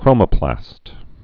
(krōmə-plăst)